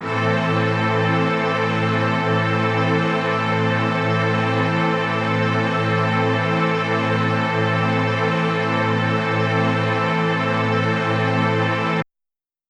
SO_KTron-Ensemble-Amin.wav